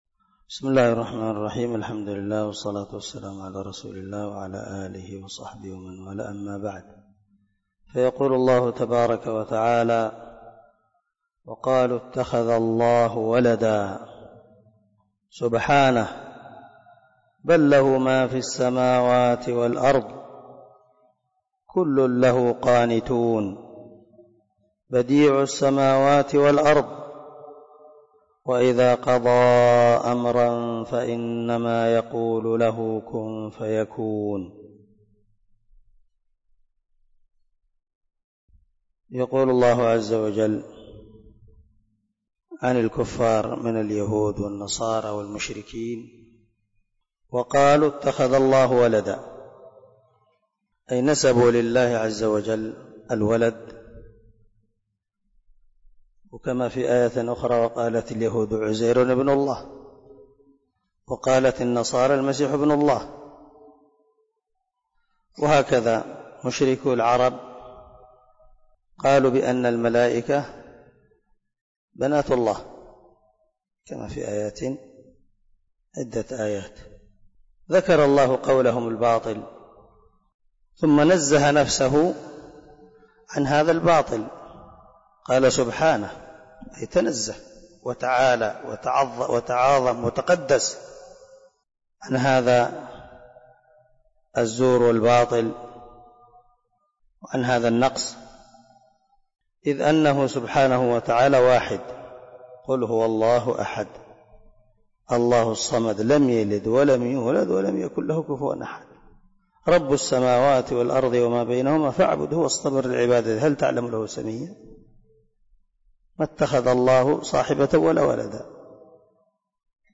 049الدرس 39 تفسير آية ( 116 – 117 ) من سورة البقرة من تفسير القران الكريم مع قراءة لتفسير السعدي